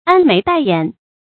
安眉帶眼 注音： ㄢ ㄇㄟˊ ㄉㄞˋ ㄧㄢˇ 讀音讀法： 意思解釋： 長了眉毛，有了眼睛。